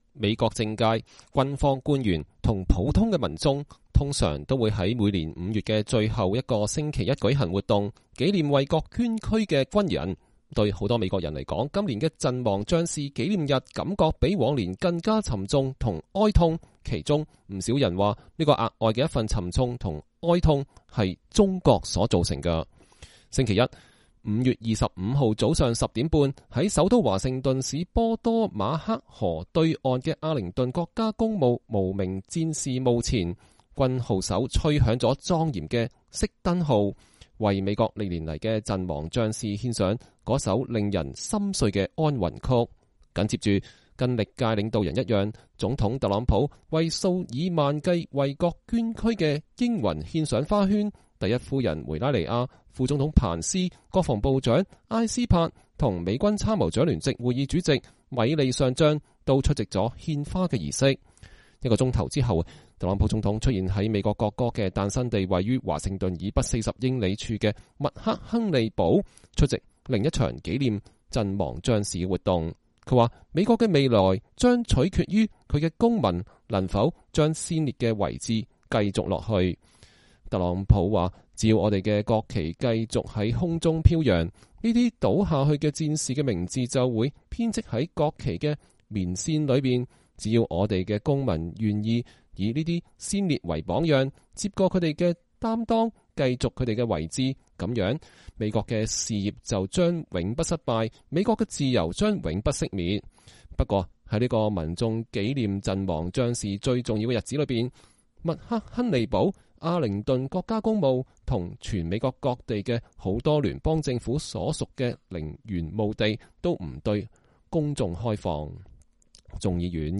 星期一（2020年5月25日）早上10點半，在首都華盛頓市波多馬克河對岸的阿靈頓國家公墓無名戰士墓前，軍號手吹響了莊嚴的《熄燈號》，為美國歷年來的陣亡將士獻上了那首令人心碎的安魂曲。